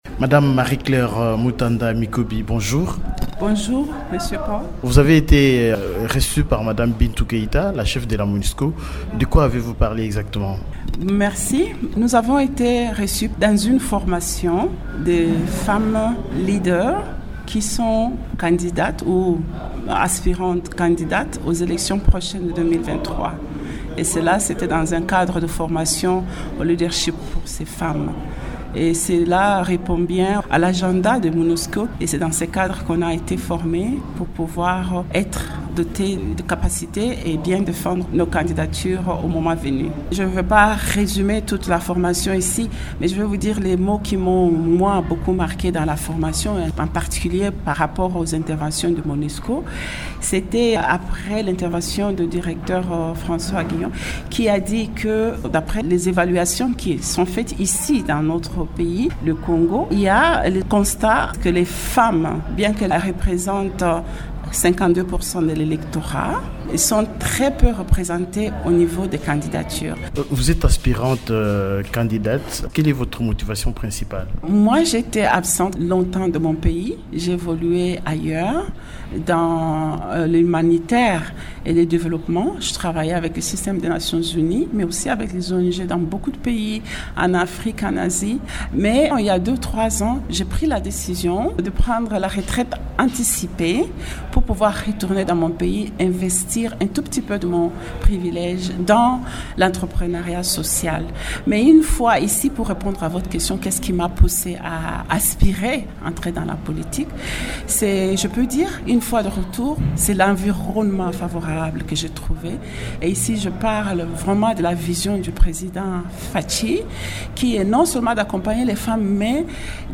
Elle l’a dit au cours d’une interview accordée à Radio Okapi le mercredi 5 avril.